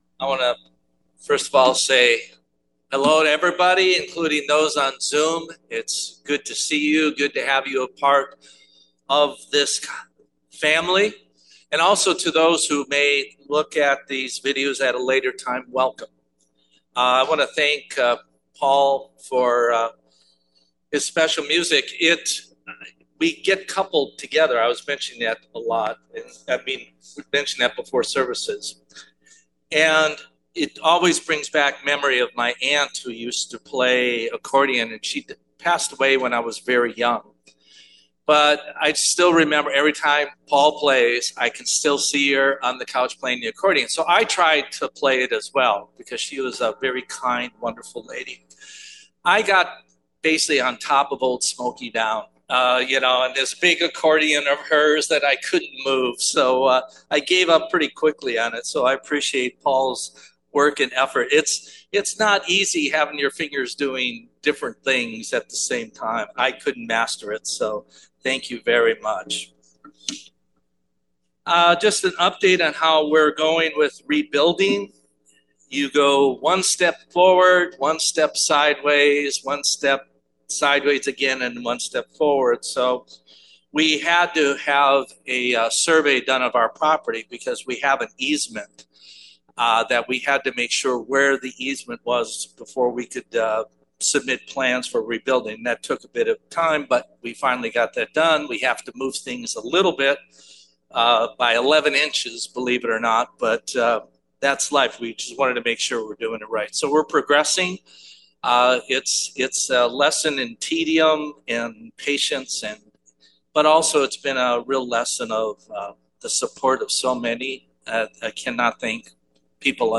Note: Due to technical issues, the initial audio quality is subpar.